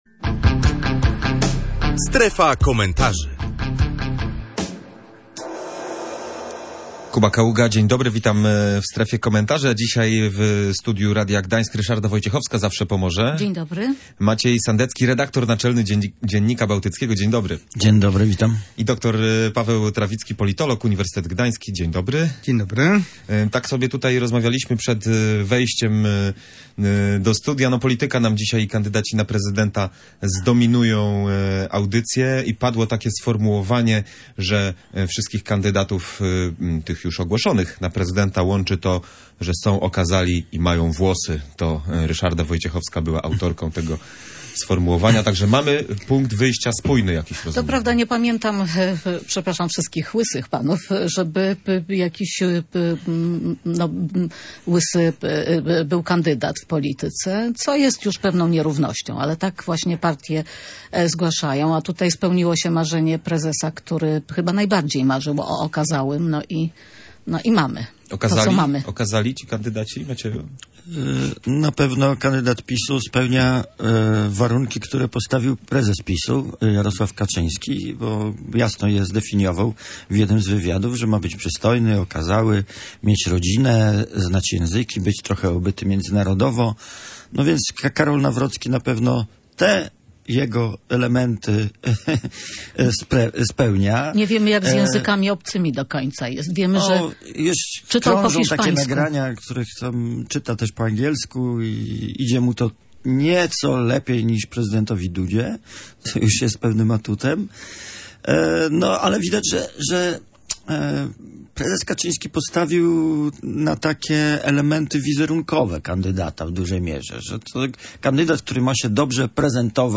Jak duże znaczenie w kampanii prezydenckiej mają kwestie wizerunkowe? W audycji "Strefa Komentarzy" między innymi nad tym zastanawiali się goście